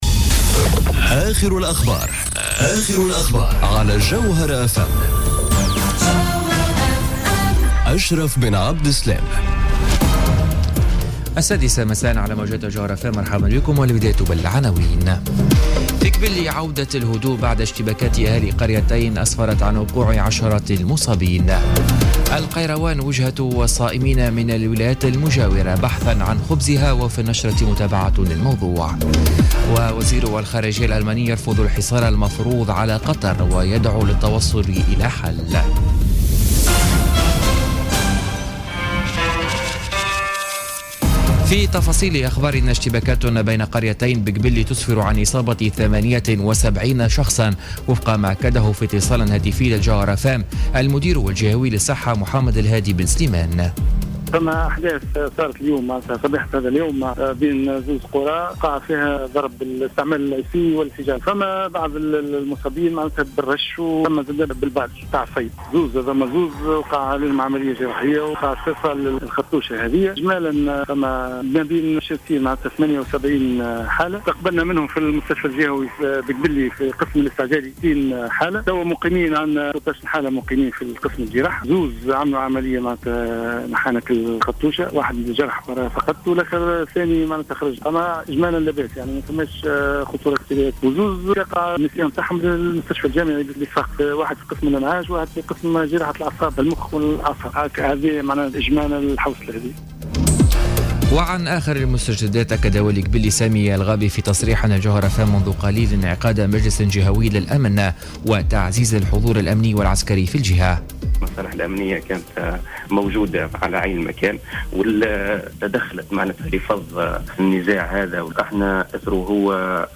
نشرة أخبار السادسة مساء ليوم السبت 10 جوان 2017